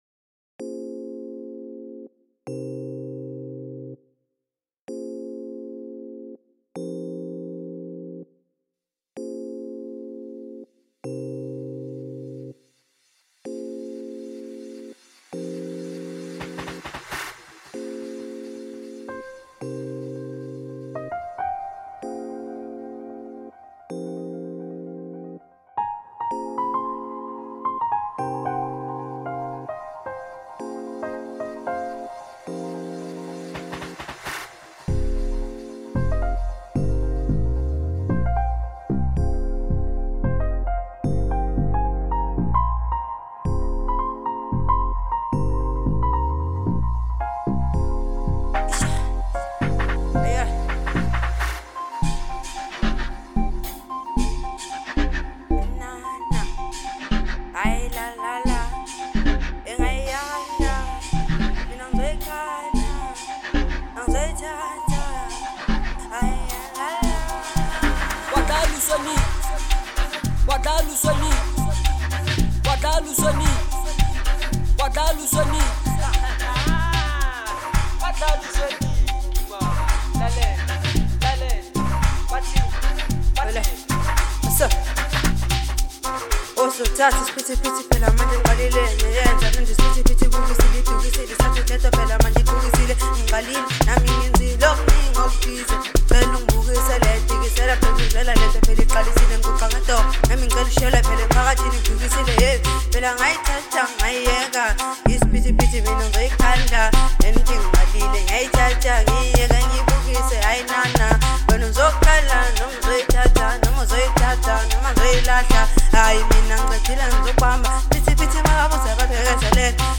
06:21 Genre : Amapiano Size